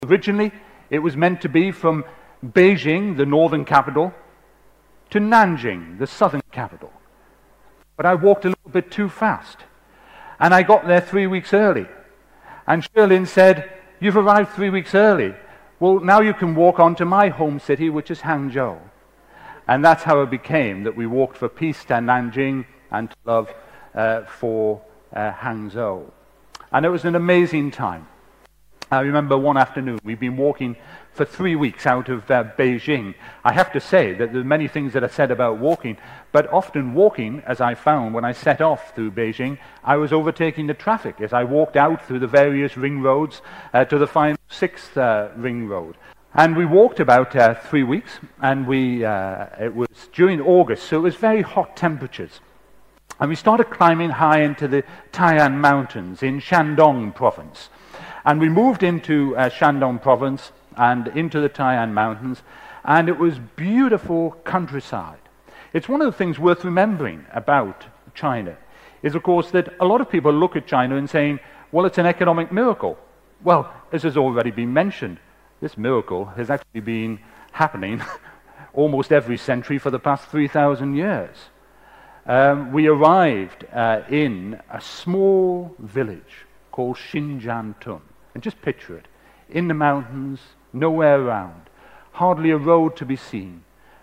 TED演讲:我难忘的一次中国行(2) 听力文件下载—在线英语听力室